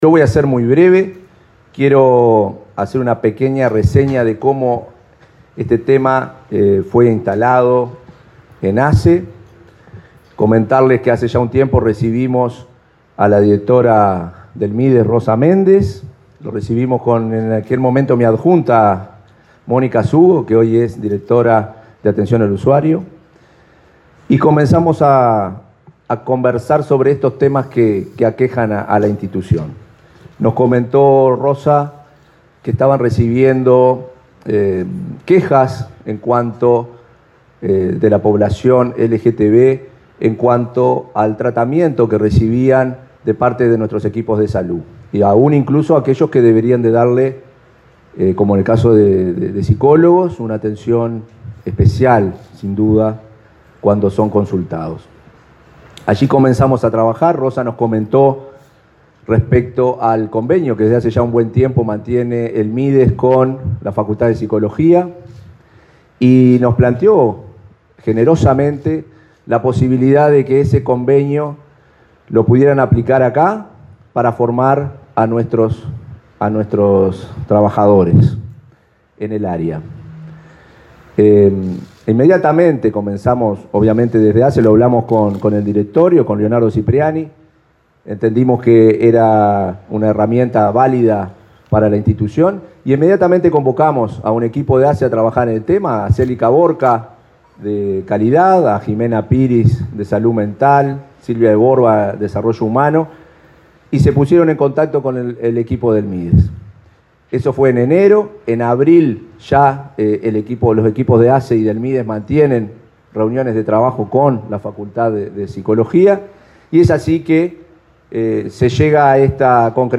Palabra de autoridades en lanzamiento de capacitación para atender a población LGTBI